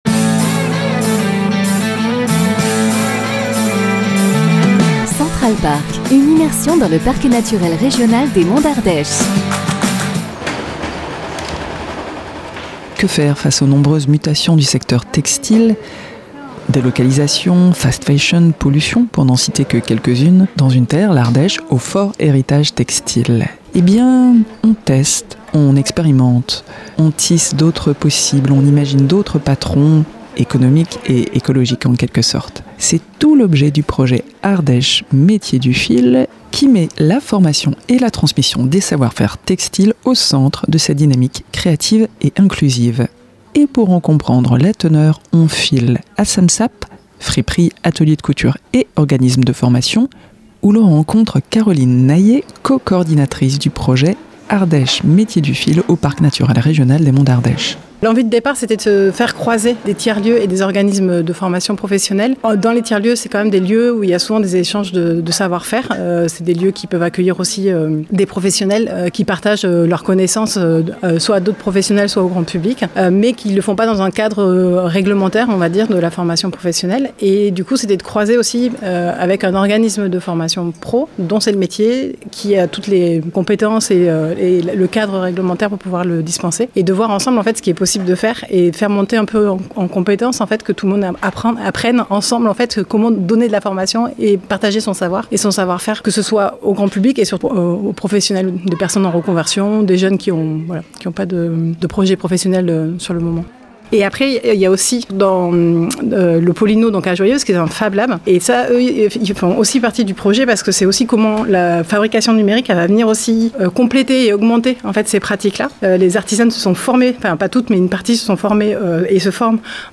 C'est au cœur de l’espace friperie de Sam'Sap à Aubenas